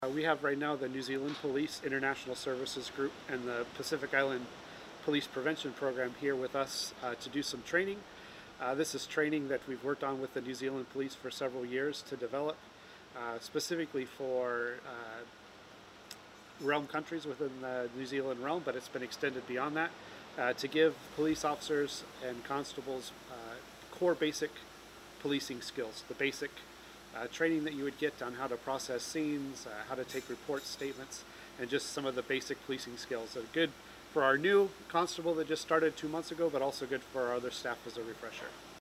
BCN News spoke with Chief of Police Tim Wilson who shared more about the purpose of the training.